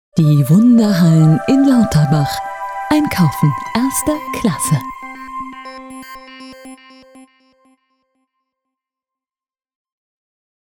Wandelbare warme Stimme. Serios oder mit einem Lächeln auf den Lippen.
Sprechprobe: Werbung (Muttersprache):